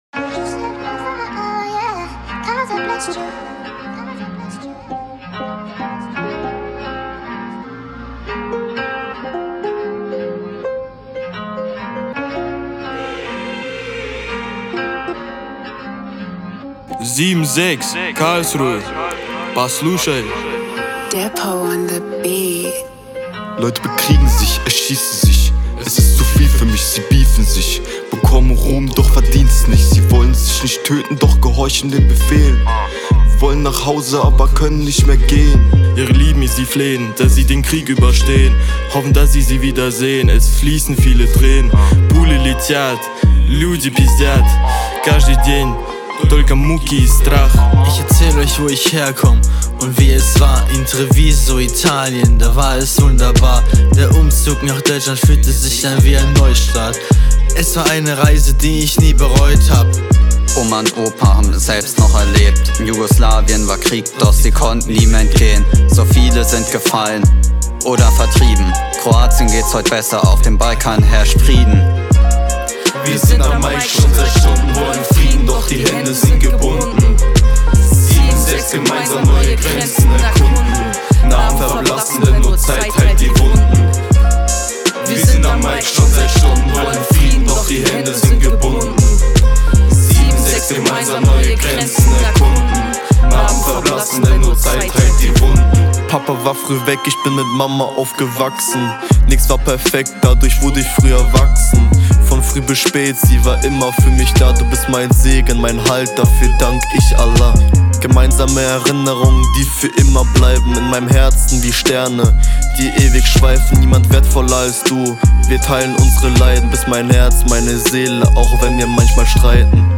So beginnt die Hook des Klassenraps der 1BK1T, den sie gemeinsam entwickelt, getextet und schließlich auch am Mikrophon gesungen haben.
Das Ergebnis spricht für sich – alle, ohne Ausnahme, haben sich getraut zu singen, so dass ein sehr persönliches, tiefgründig akustisches Klassenbild entstand.